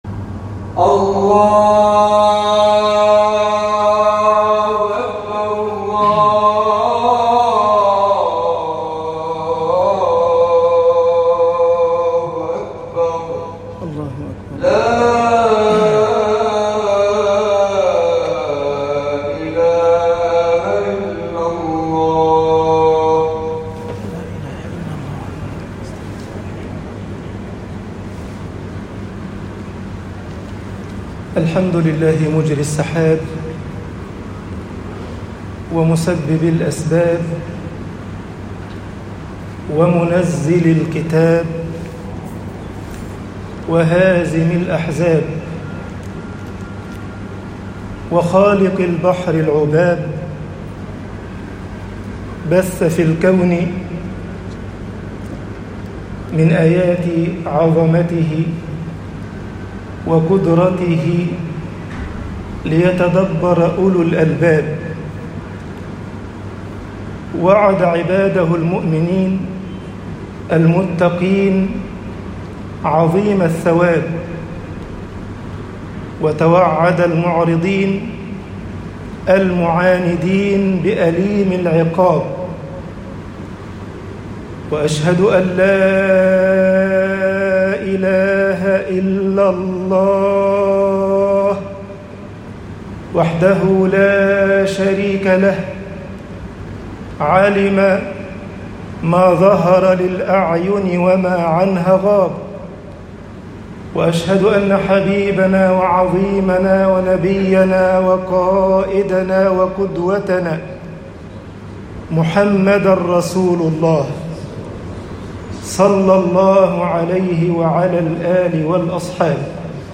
خطب الجمعة - مصر الجزاء مِن جِنْسِ الْعَمَلِ طباعة البريد الإلكتروني التفاصيل كتب بواسطة